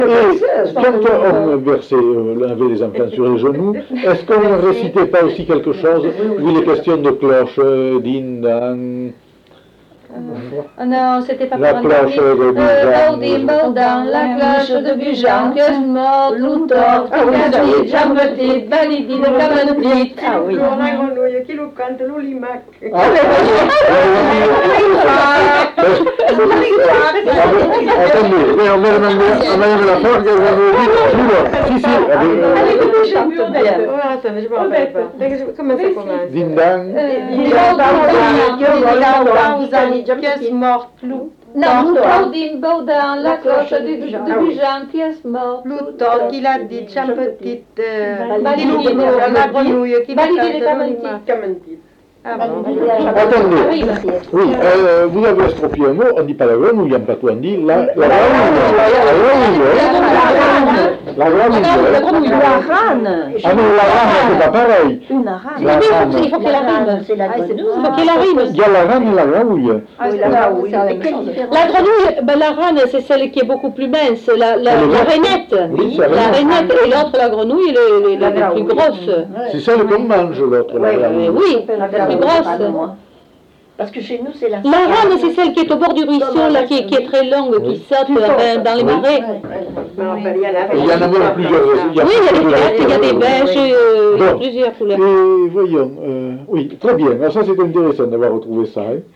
Aire culturelle : Grandes-Landes
Lieu : Salles
Type de voix : voix de femme
Production du son : récité
Classification : formulette enfantine